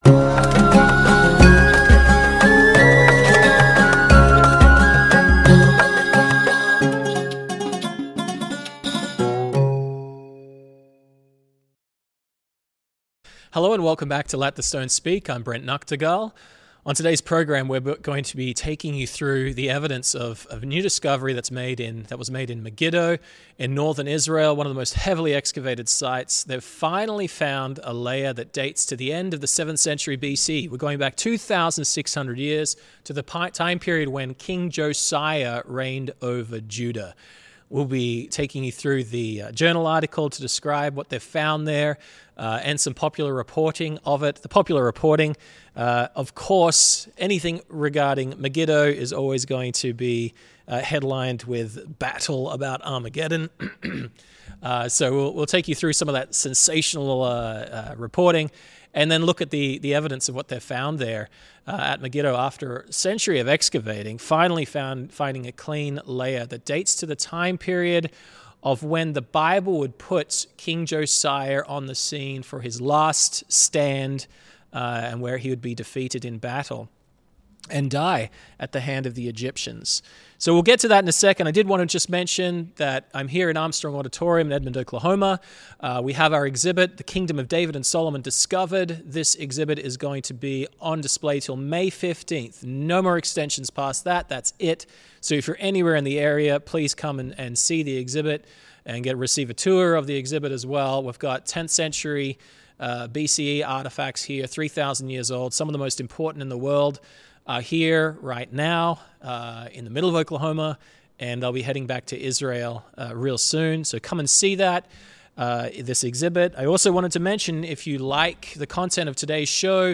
This is at the precise time the Bible says King Josiah traveled to Megiddo to war against Egypt. On today’s program, host